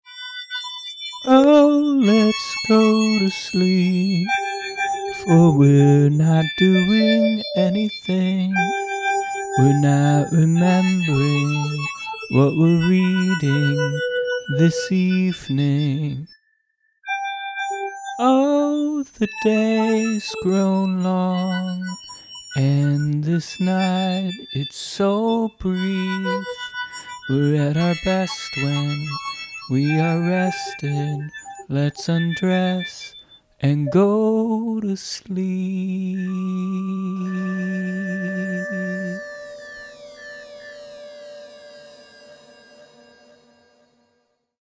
G, A, G, D
verse verse
The background is my guitar through tremolo, delay, octave, and a bit crushing pedal.